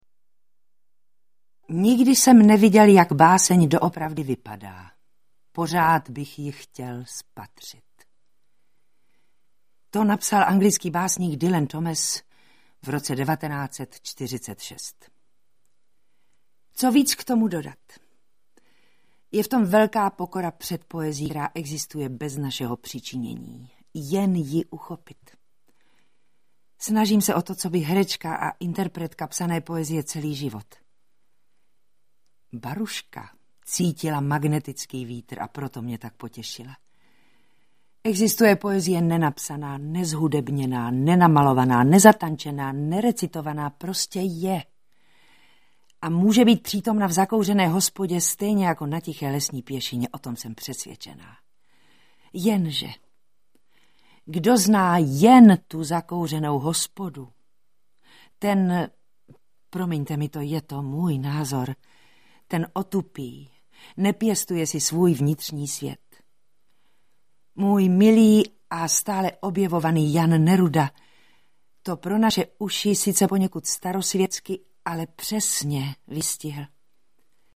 Zvuková adaptace jevištního recitálu
flétna
kytara